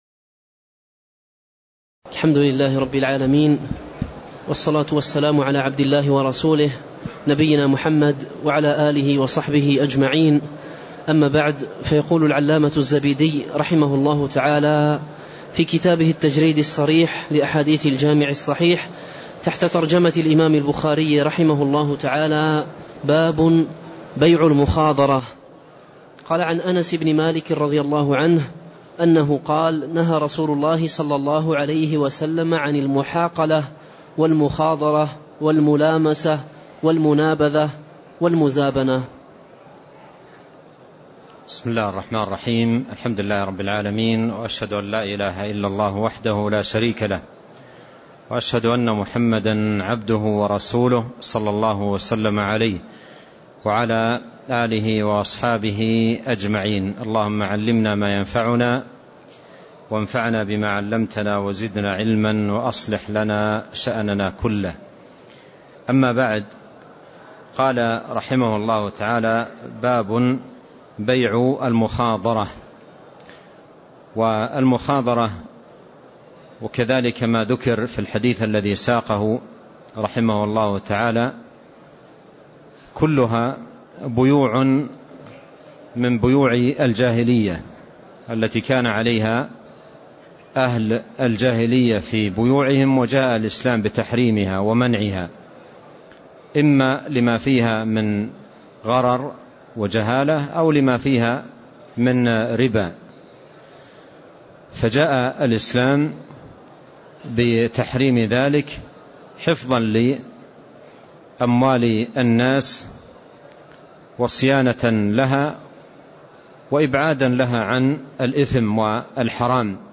تاريخ النشر ٩ محرم ١٤٣٥ هـ المكان: المسجد النبوي الشيخ